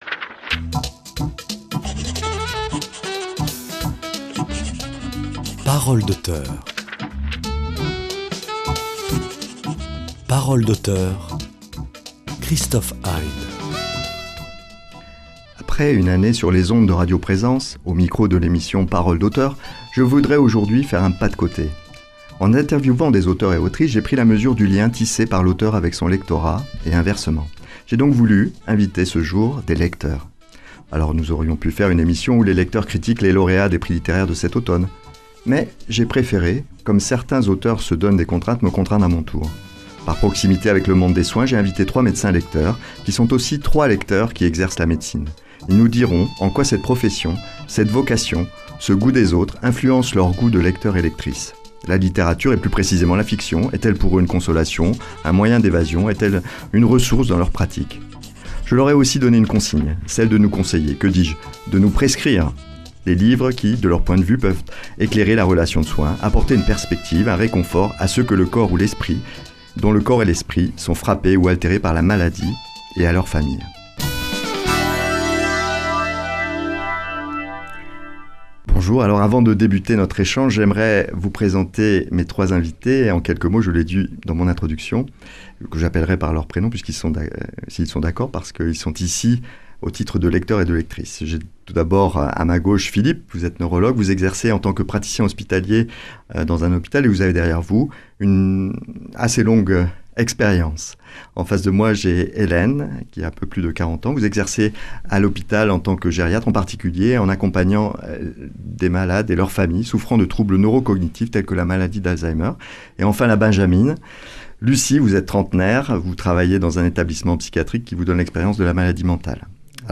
Trois lecteurs, trois médecins, trois subjectivités s’interrogent sur le lien qui unit littérature et médecine.